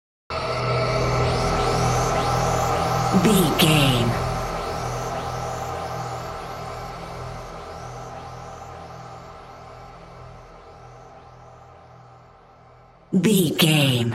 Sound Effects
Atonal
scary
ominous
haunting
eerie